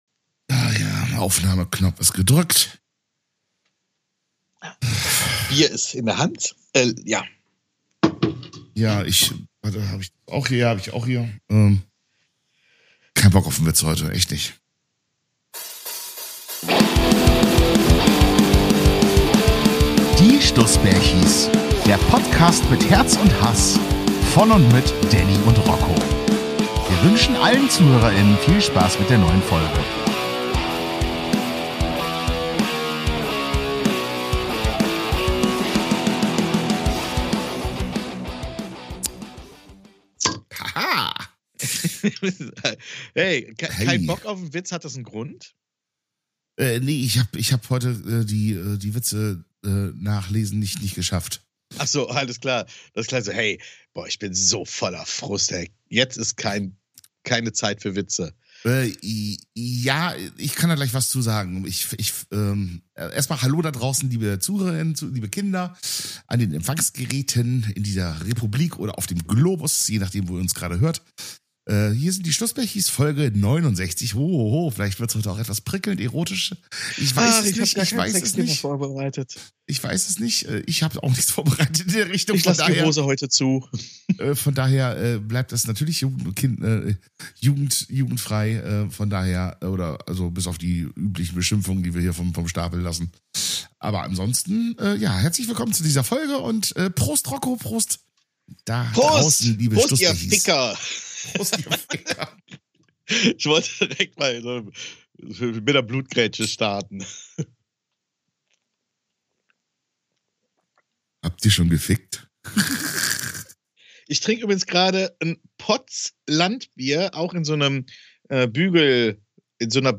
Wir kämpfen immer noch gegen unseren Husten an.